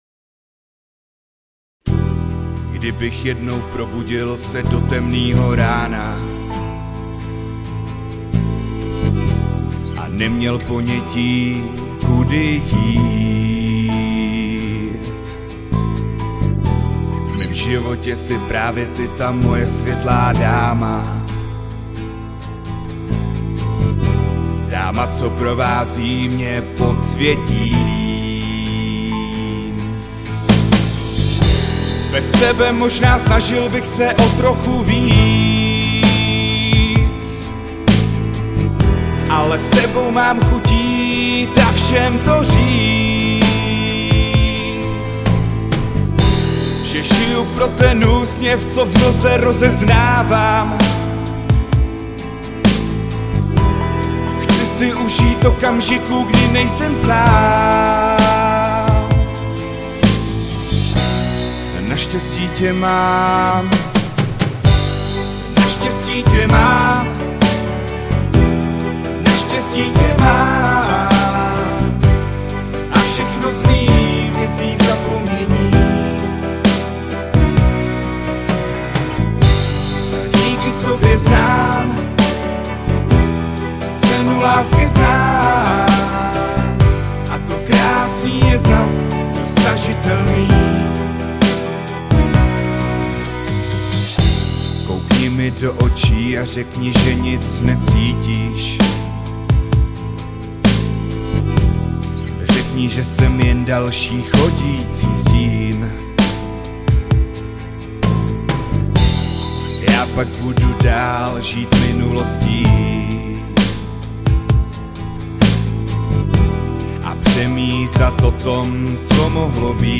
Žánr: Pop/Rock/Folk